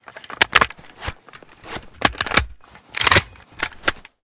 aks74u_reload_empty.ogg